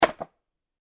Golpe de piedra contra una calabaza
Sonidos: Acciones humanas